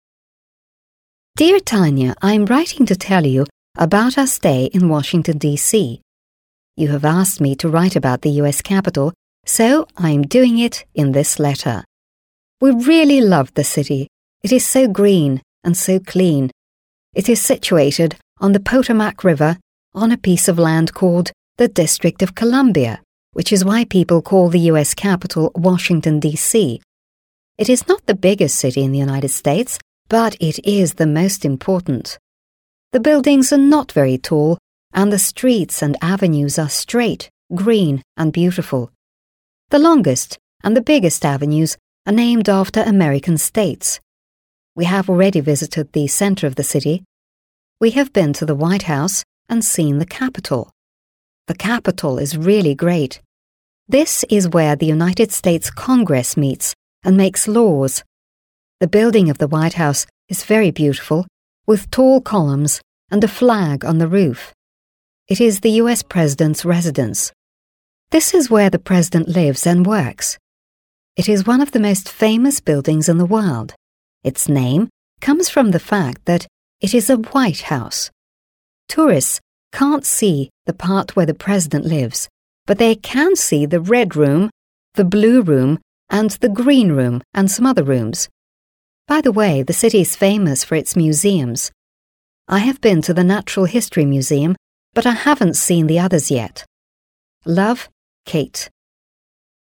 (Это задание для устного выполнения. Включите аудиозапись и следите за текстом, повторяя интонацию диктора).
(Это задание на отработку произношения. Рекомендуется сначала прослушать аудиозапись, обращая внимание на интонацию и паузы, а затем прочитать текст самостоятельно, копируя манеру диктора).